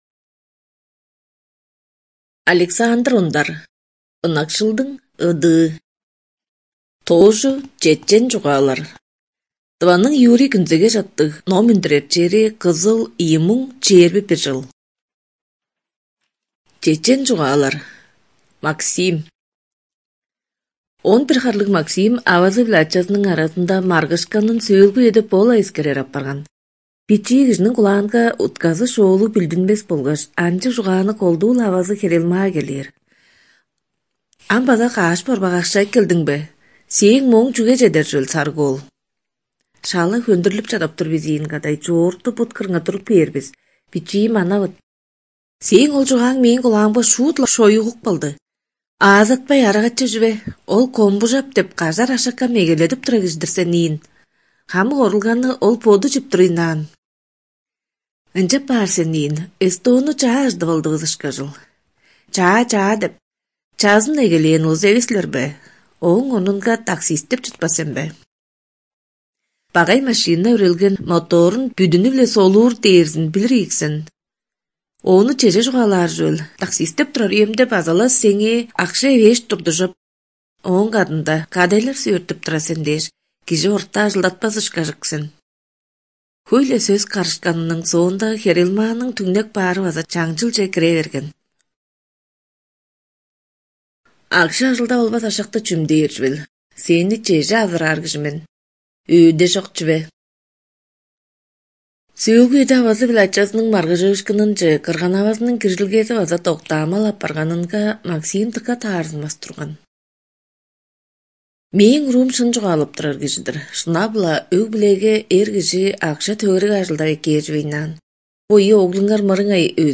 Студия звукозаписиТувинская республиканская специальная библиотека для незрячих и слабовидящих